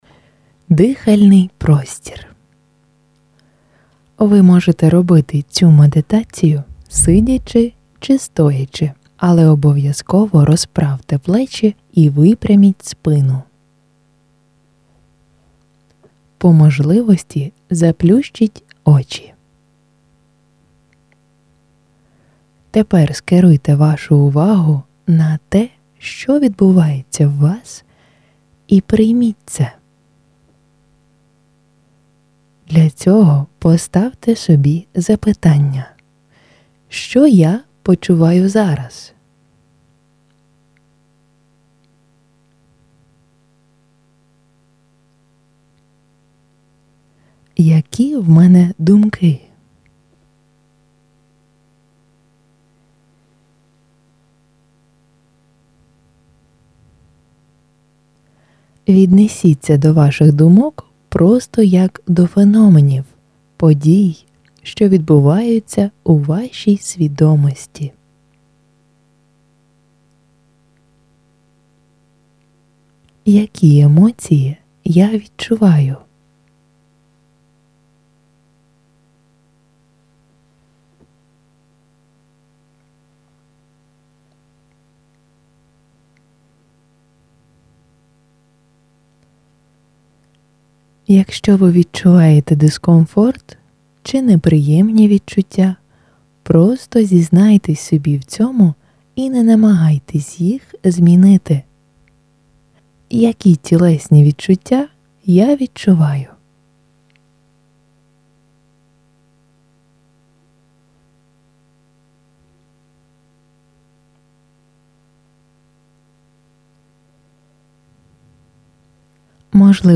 Жанр: Blues.